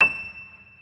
piano-sounds-dev
Vintage_Upright
e6.mp3